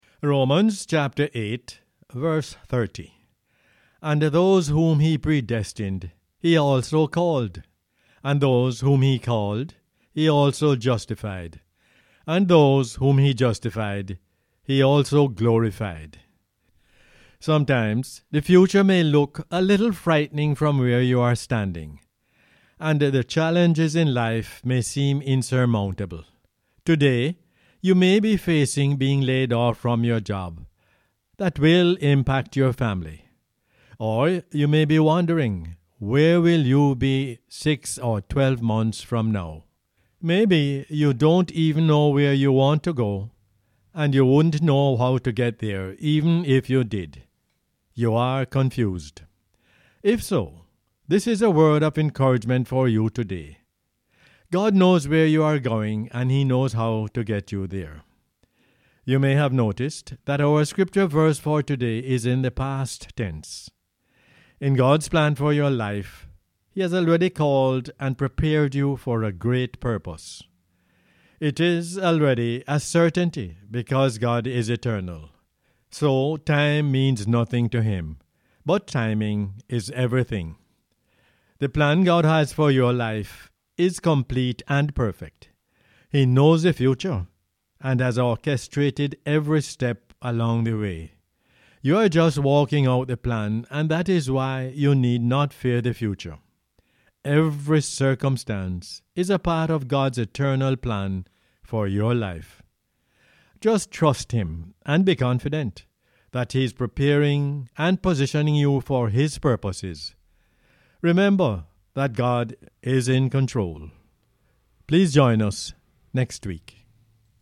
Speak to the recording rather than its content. Romans 8:30 is the "Word For Jamaica" as aired on the radio on 24 April 2020.